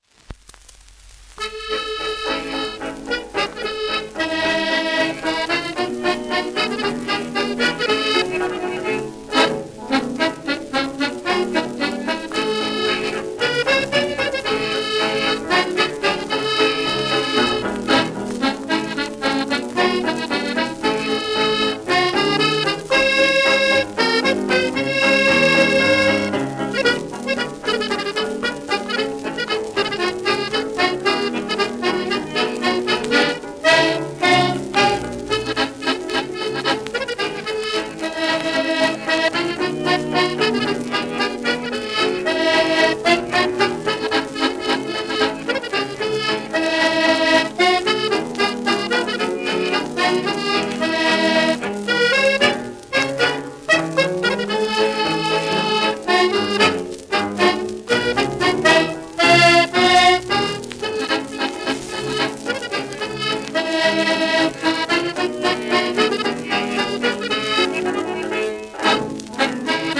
、ヴァイオリン、バンジョー、ボーカル不詳
1930年頃の録音
『ミュゼットの父」、「ミュゼットの王様」とも呼ばれるフランスのアコーディオン奏者。